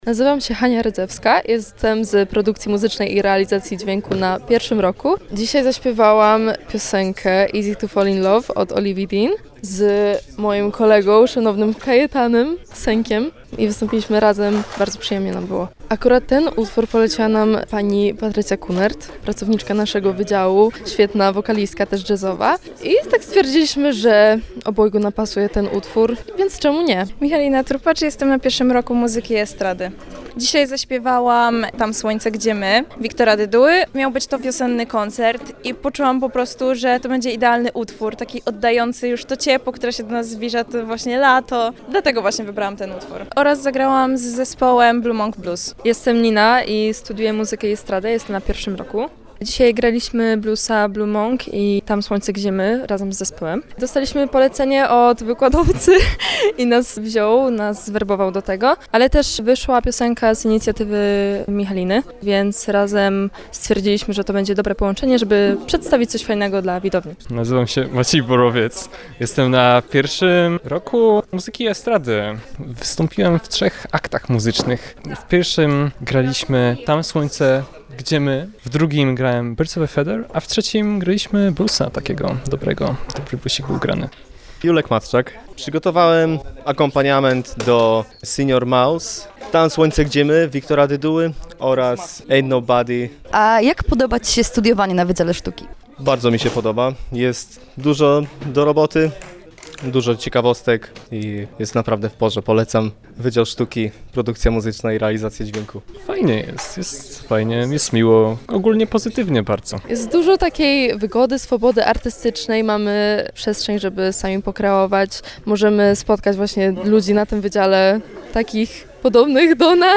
„Wiosenna Scena Wydziału Sztuki” – to inicjatywa studentów kierunków artystycznych realizowanych na Uniwersytecie Warmińsko-Mazurskim w Olsztynie. W piątek 17 kwietnia wystąpili w Auli Teatralnej Wydziału Humanistycznego, prezentując różnorodny repertuar.